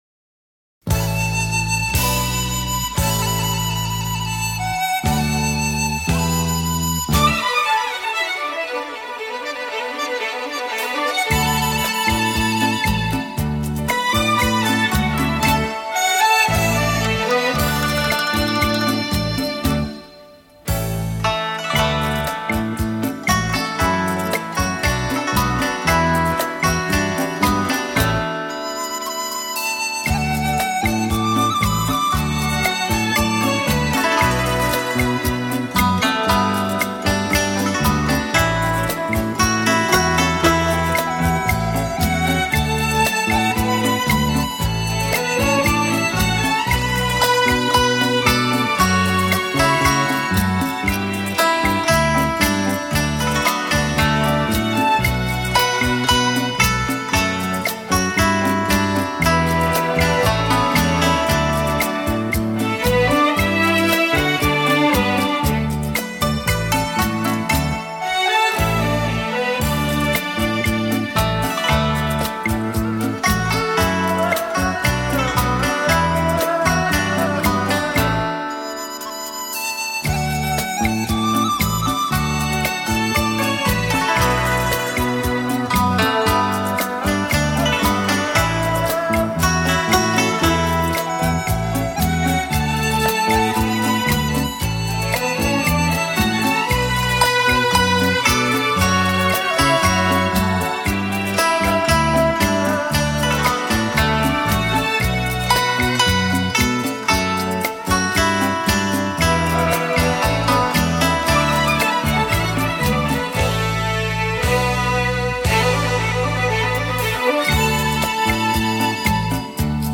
古筝演奏